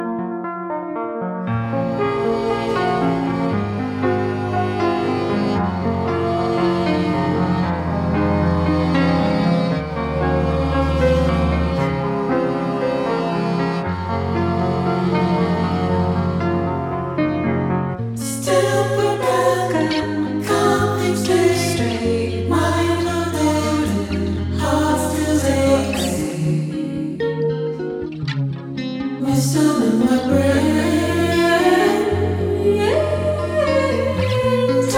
Жанр: R&B / Альтернатива / Соул